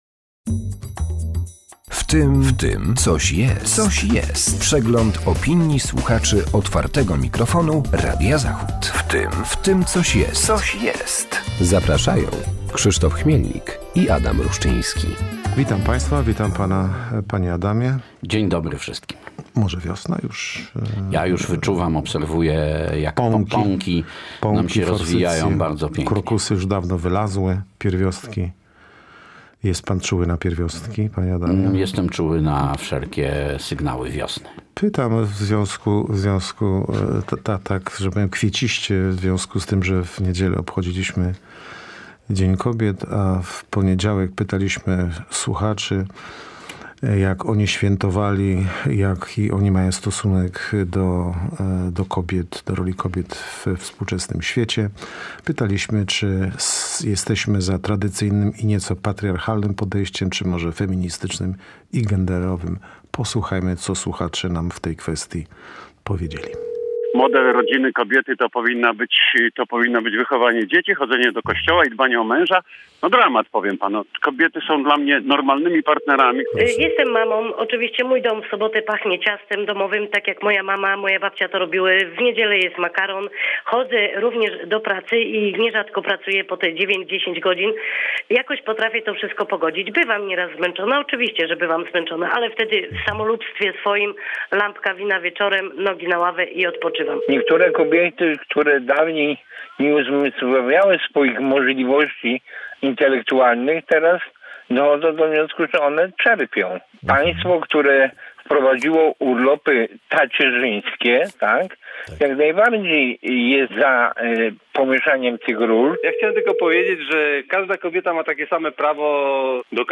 W cotygodniowej audycji przypominamy głosy słuchaczy Otwartego Mikrofonu oraz komentujemy tematy z mijającego tygodnia.